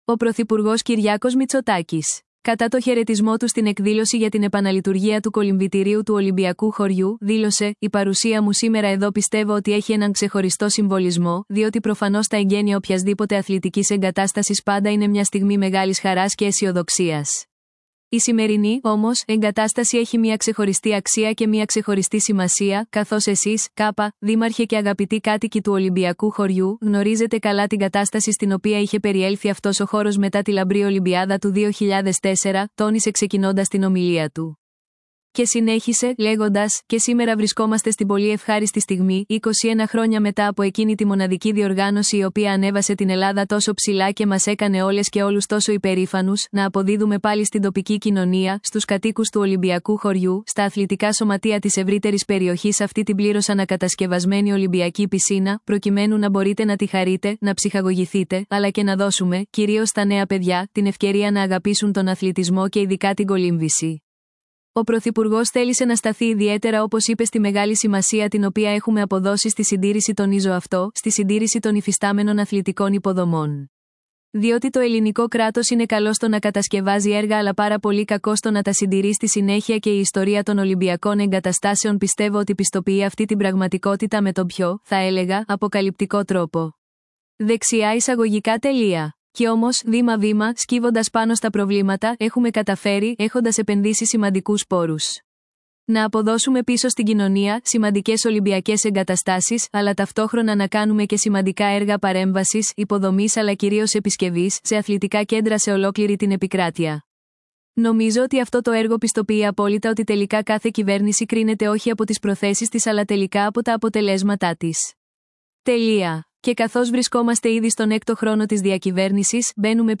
, Ο πρωθυπουργός Κυριάκος Μητσοτάκης, κατά τον χαιρετισμό του στην εκδήλωση για την επαναλειτουργία του κολυμβητηρίου του Ολυμπιακού Χωριού, δήλωσε: «Η